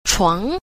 7. 床 – chuáng – sàng (giường)